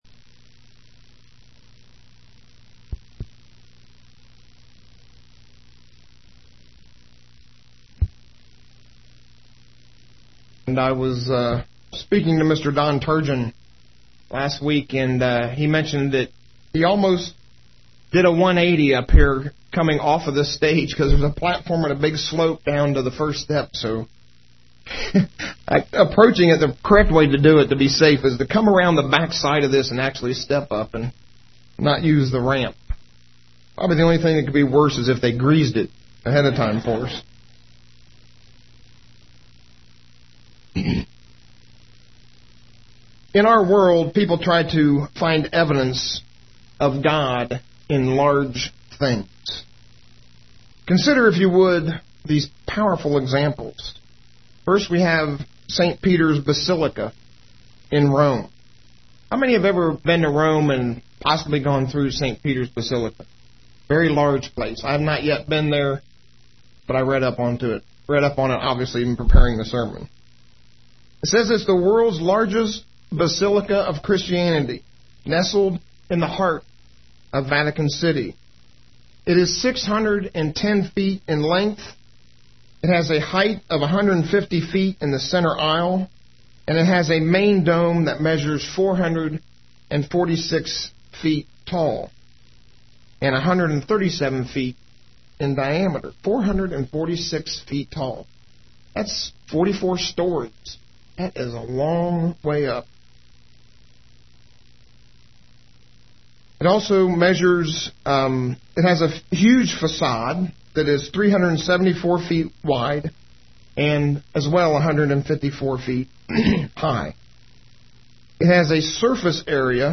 UCG Sermon Notes Notes: Humans see evidence of God’s existence in large things.